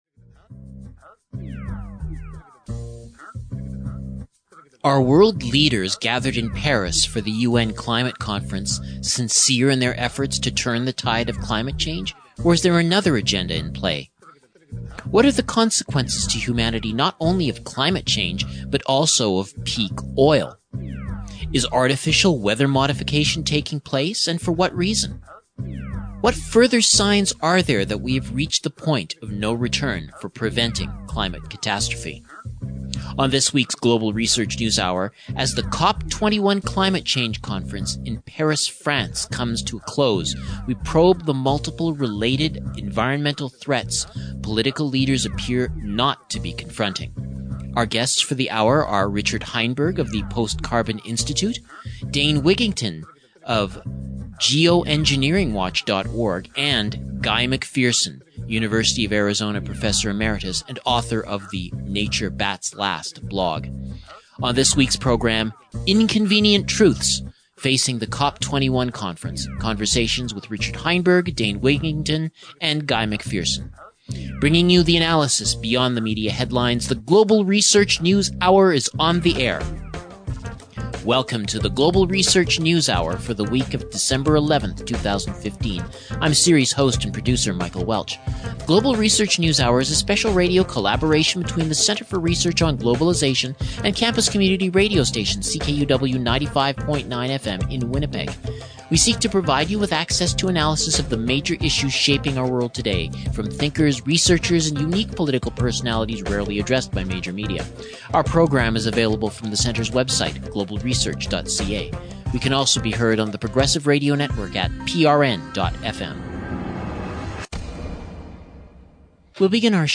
Conversations with Richard Heinberg